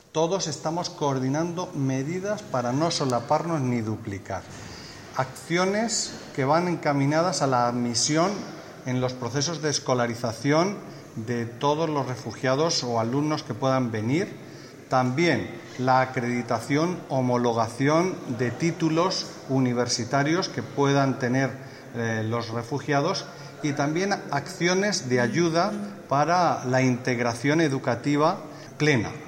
Declaración del secretario de Estado de Educación, Formación Profesional y Universidades, Marcial Marín.